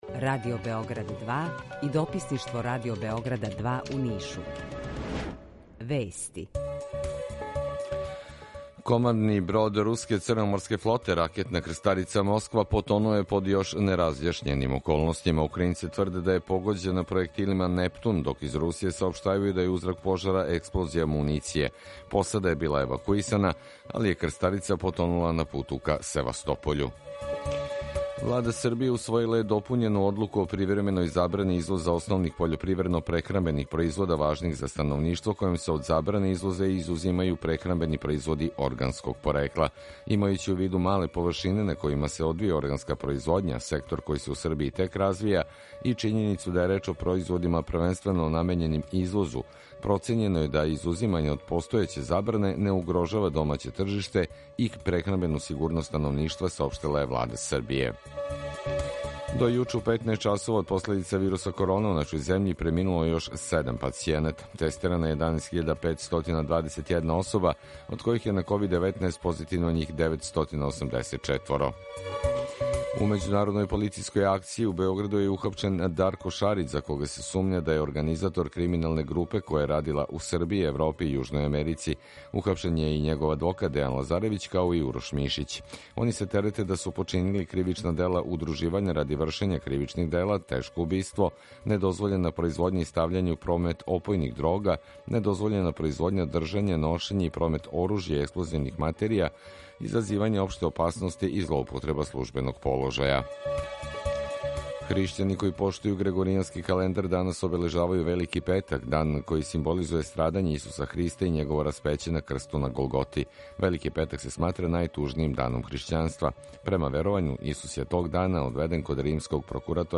Емисију реализујемо заједно са студиом Радија Републике Српске у Бањалуци и са Радио Новим Садом.
У два сата, ту је и добра музика, другачија у односу на остале радио-станице.